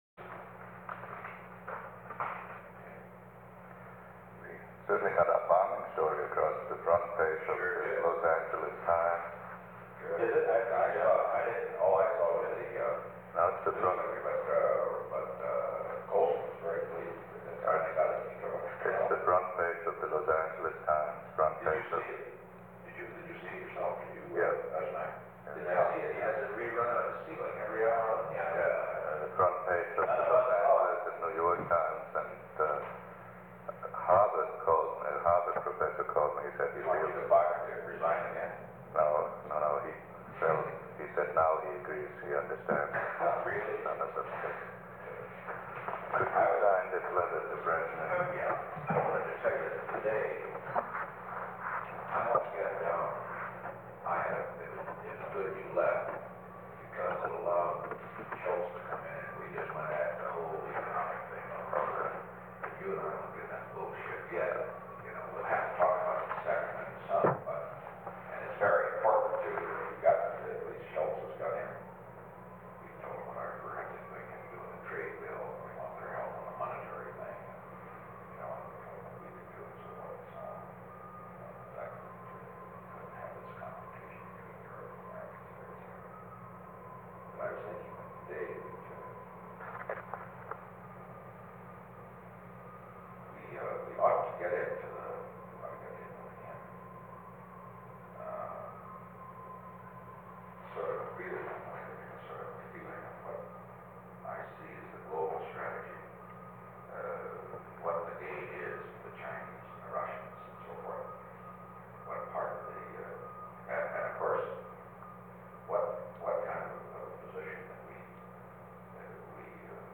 Conversation: 847-014
Recording Device: Oval Office
On February 2, 1973, President Richard M. Nixon and Henry A. Kissinger met in the Oval Office of the White House from 12:22 pm to 12:30 pm. The Oval Office taping system captured this recording, which is known as Conversation 847-014 of the White House Tapes.
The President met with Henry A. Kissinger and H. R. (“Bob”) Haldeman.